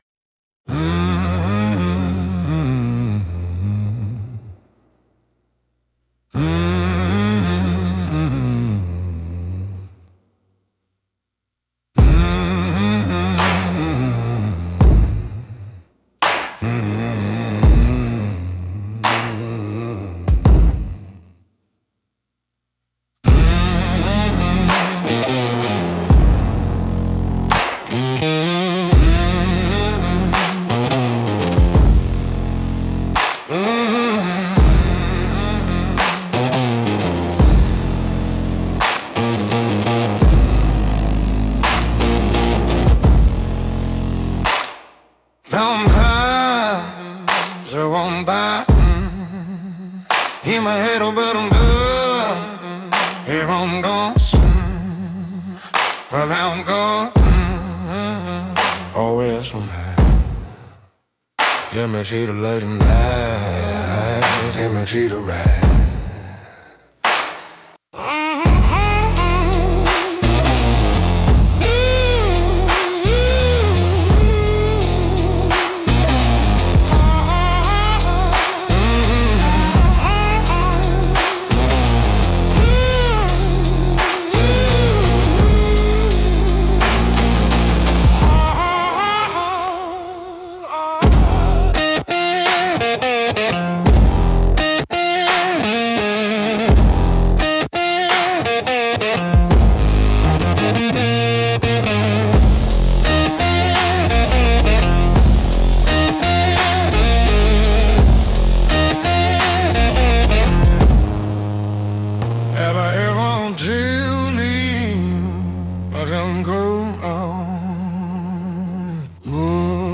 Instrumental - Digital Delta Rising 3.07